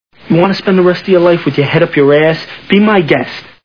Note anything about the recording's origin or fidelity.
The Sopranos TV Show Sound Bites